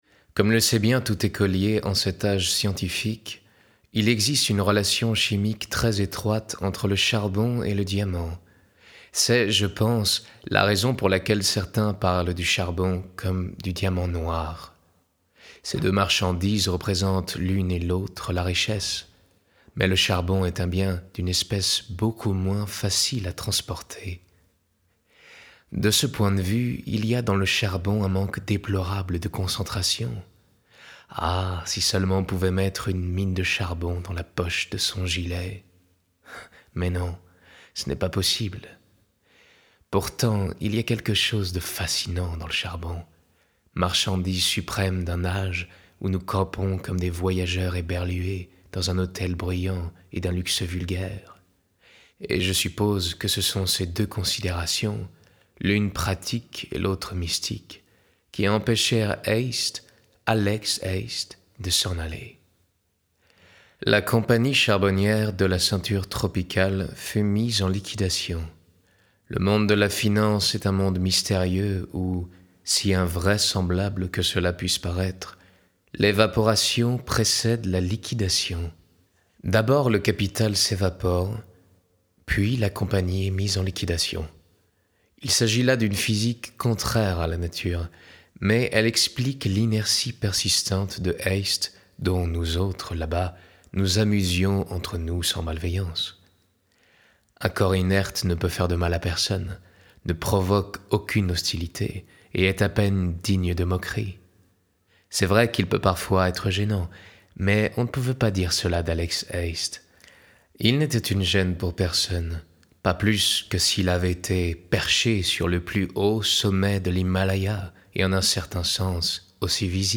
Voix off
28 - 35 ans - Baryton